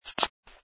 sfx_fturn_female04.wav